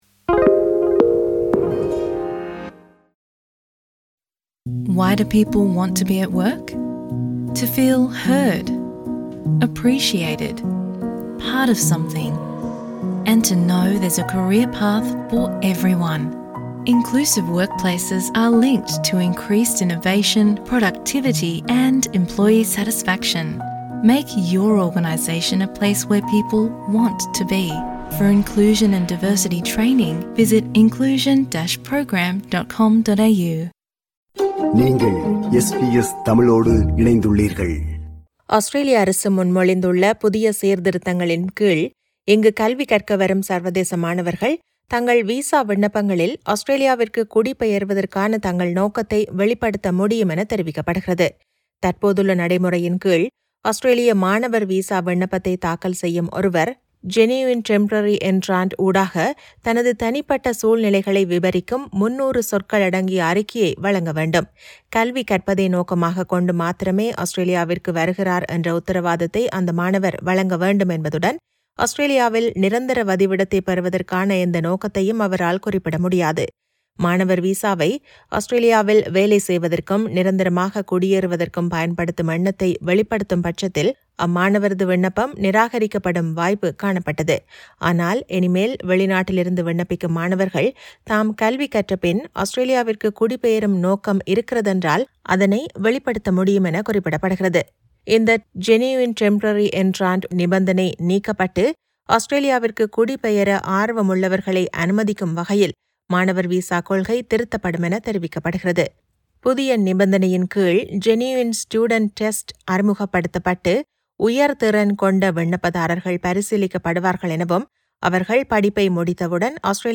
செய்தி விவரணத்தை முன்வைக்கிறார்